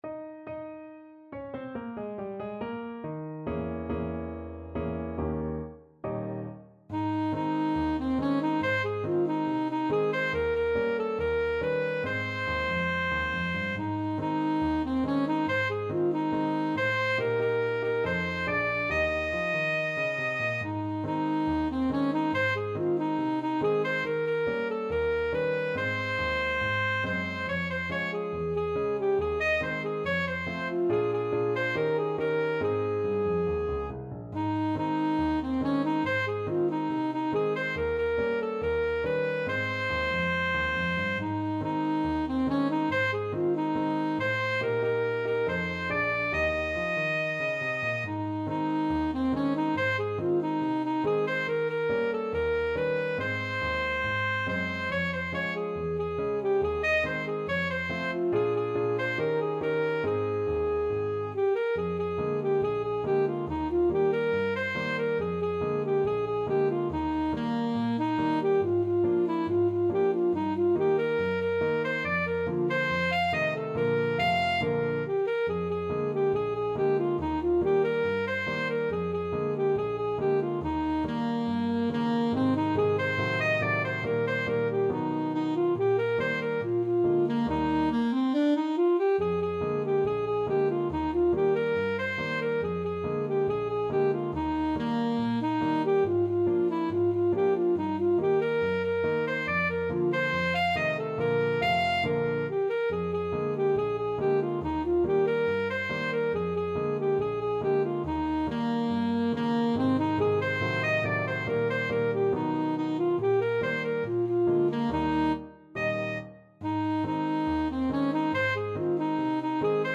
Alto Saxophone
2/4 (View more 2/4 Music)
Not fast Not fast. = 70
Ab major (Sounding Pitch) F major (Alto Saxophone in Eb) (View more Ab major Music for Saxophone )
Jazz (View more Jazz Saxophone Music)